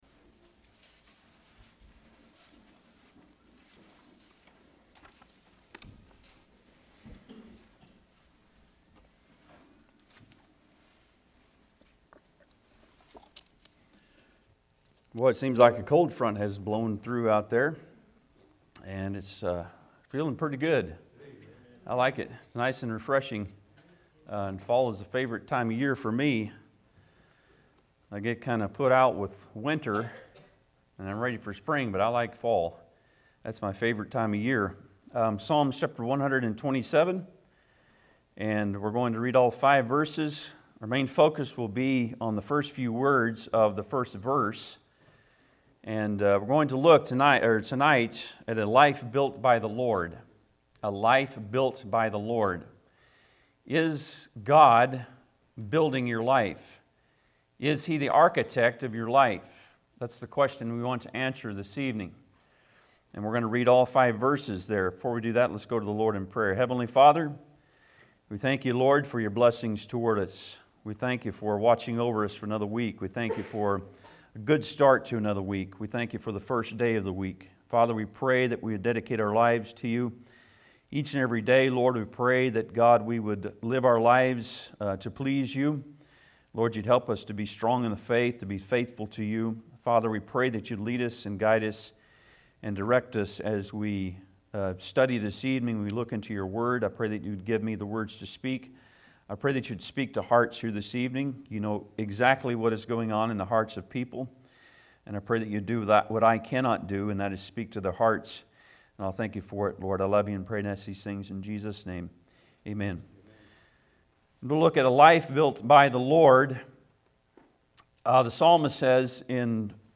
Psalms 127 Service Type: Sunday pm Bible Text